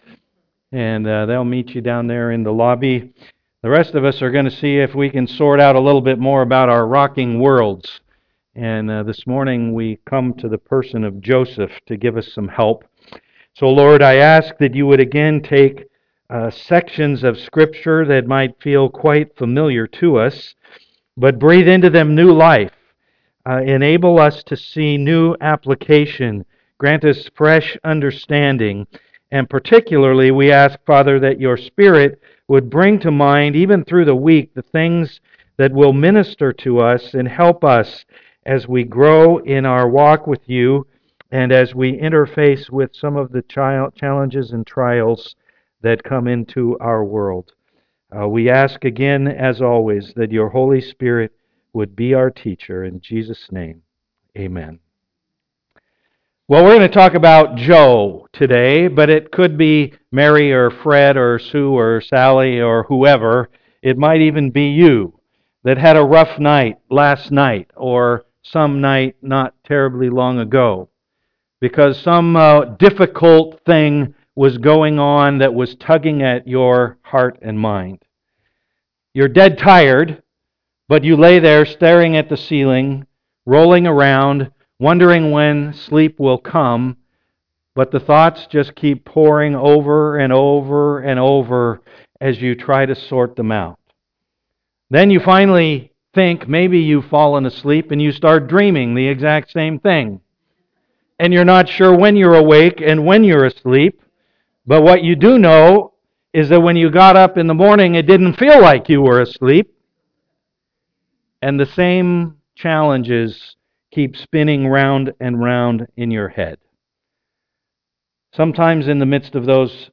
Rock My World Passage: Matthew 1:18-25 Service Type: am worship Discussion questions found on "bulletin" link below.